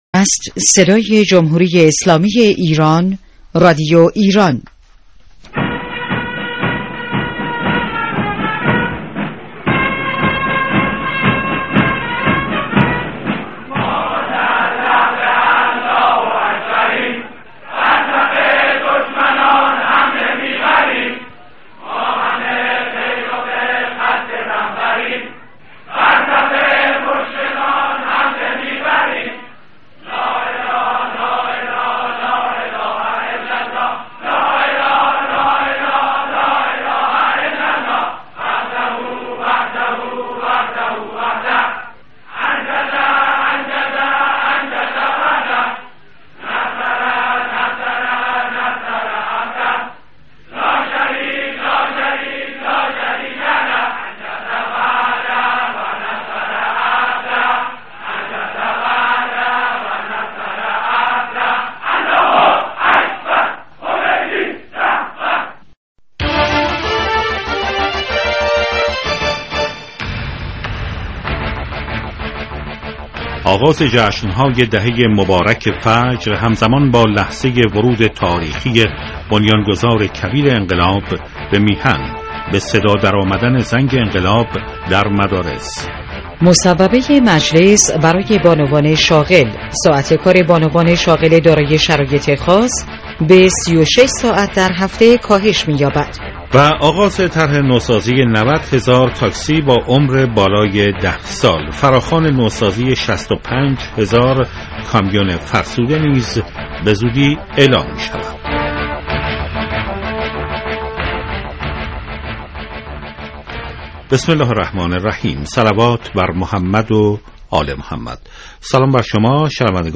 عناوین اخبار 12 بهمن 1394 بسته نیمروزی که شامل سرخط اخبار اقتصادی، سیاسی، خبر های ورزشی و پیک نیمروزی است.